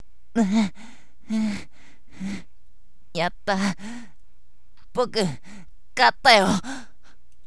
青年ボイスだけではと思って少年